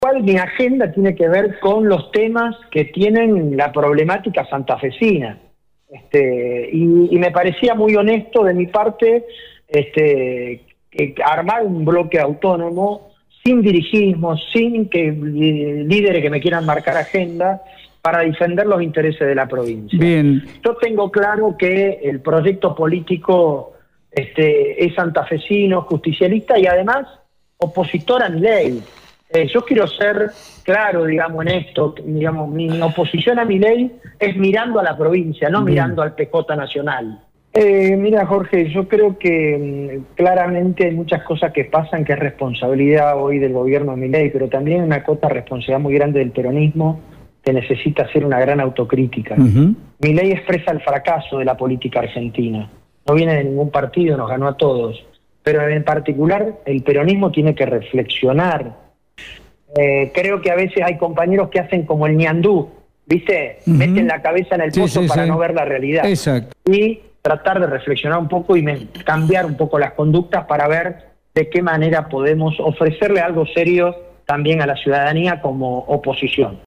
En EME, Mirabella asegura que seguirá siendo un legislador justicialista opositor a Milei desde Santa Fe.
DIP-NAC-ROBERTO-MIRABELLA-SOBRE-SU-NUEVO-MONOBLOQUE-DEFENDAMOS-SANTA-FE.mp3